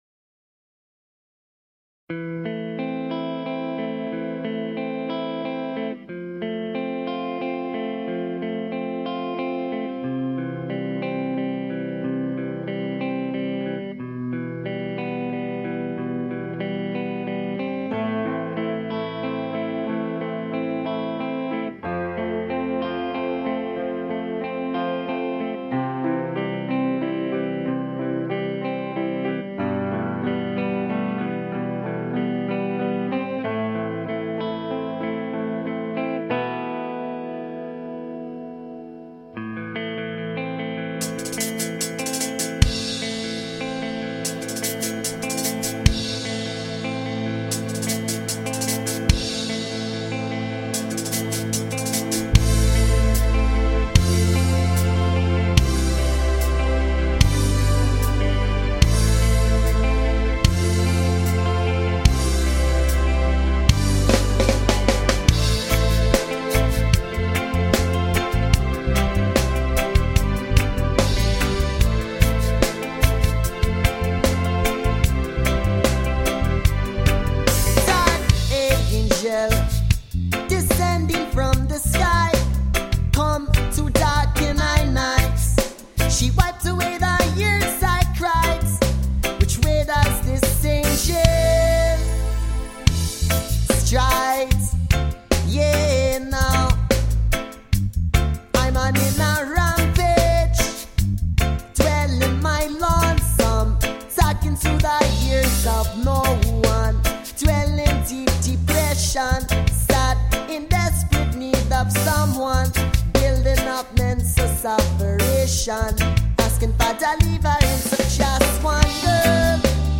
Vocal, Guitarra rítmica
Bateria
Percussão
Baixo